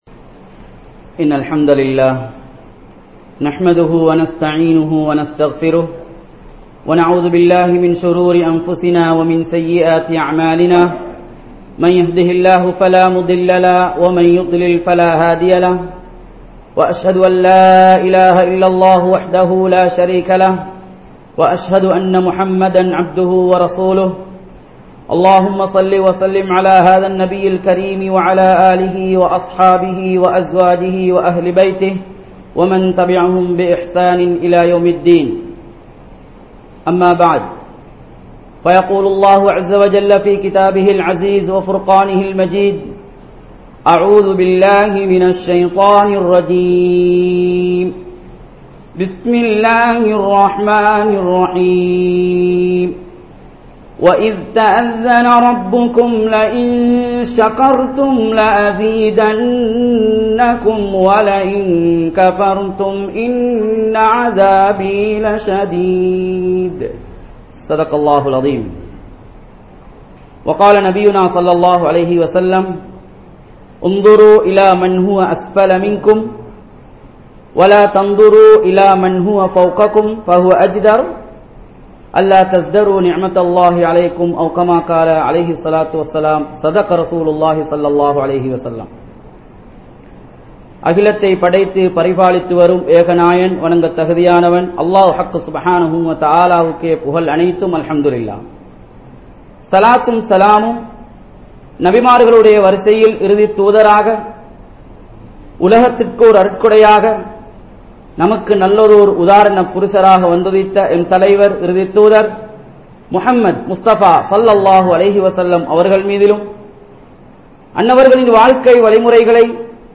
Allah Thanthulla Niumath (அல்லாஹ் தந்துள்ள நிஃமத்) | Audio Bayans | All Ceylon Muslim Youth Community | Addalaichenai
Masjidhul Hakam Jumua Masjidh